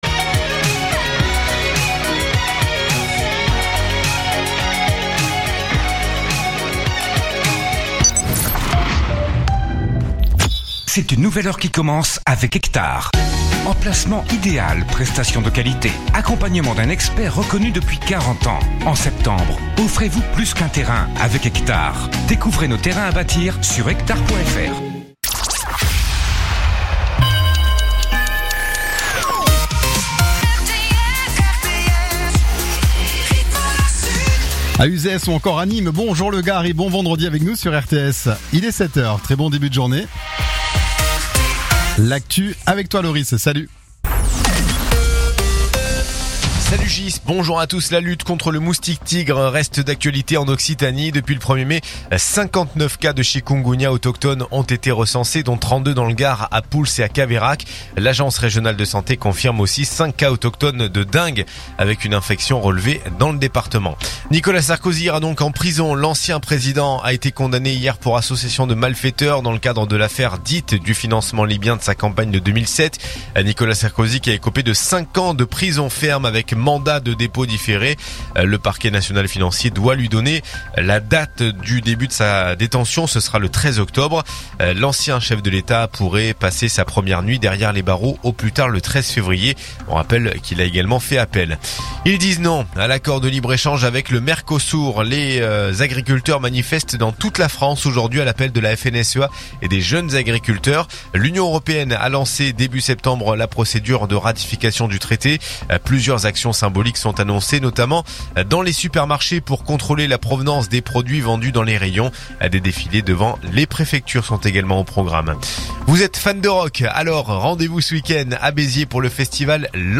info_nimes_518.mp3